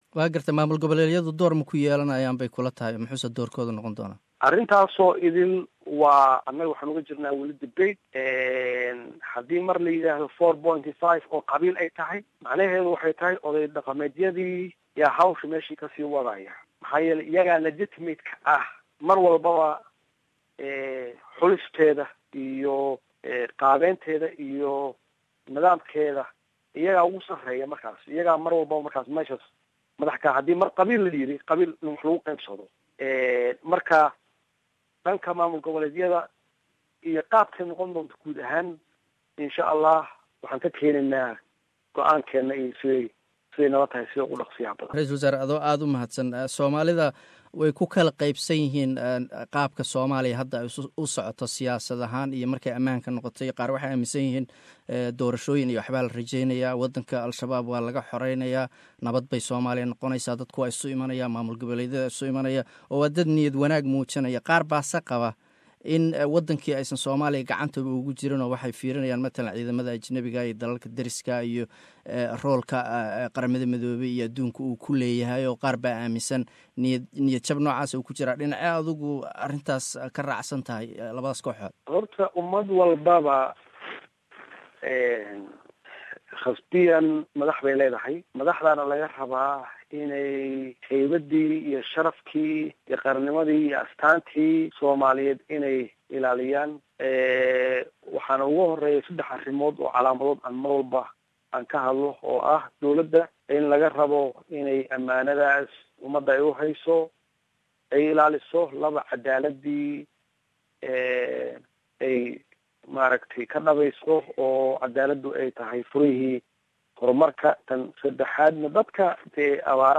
Mudane Farmaajo uu warramayay Idaacadda Australia Laanteeda Afka Soomaaliya ayaa la weydiiyay doorka maamul-goboleedka dhismaha ay ku yeelan karaan Somalia wuxuuna ku jawaabay.
DHAGEYSO_Wareysi_RW_Hore_Somalia.mp3